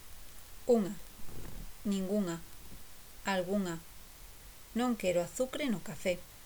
/ ŋ /